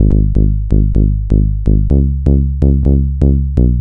Straight Bass Ab 126.wav